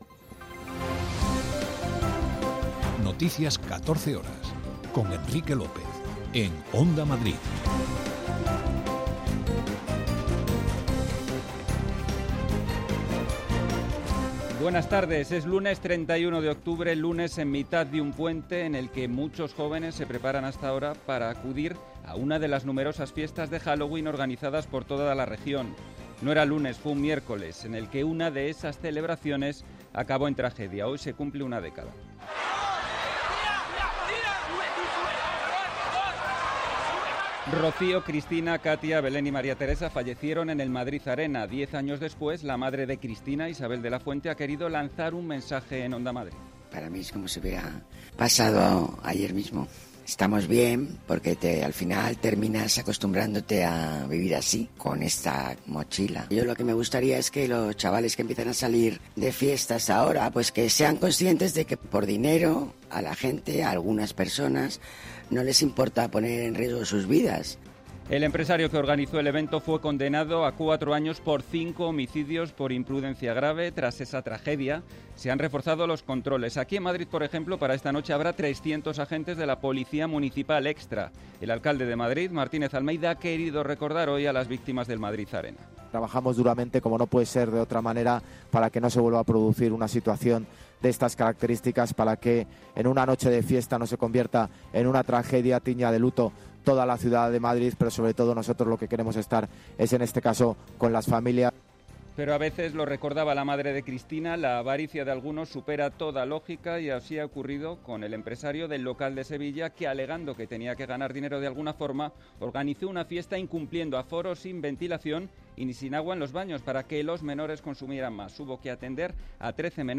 Noticias 14 horas 31.10.2022